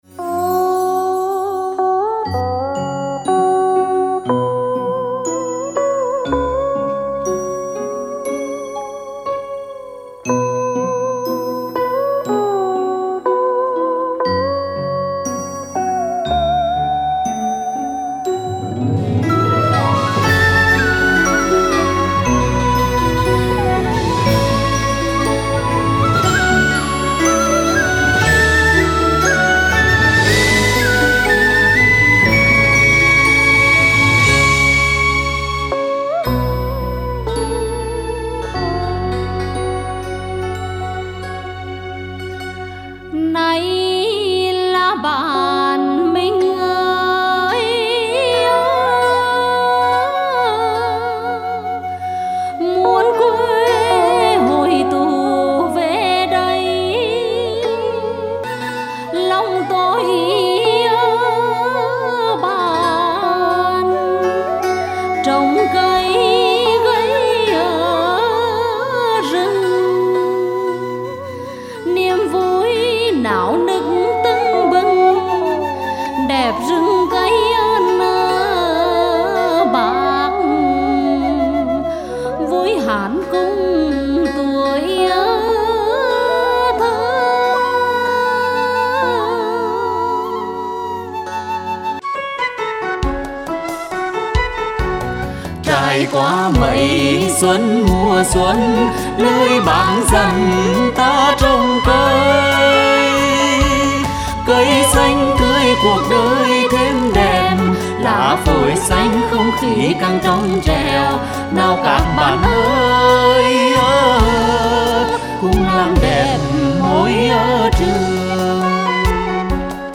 - Tên tác phẩm dự thi: 2.1. Vì màu xanh trái đất (dành cho thiếu nhi trình diễn) (Tổ khúc dân ca Nghệ Tĩnh).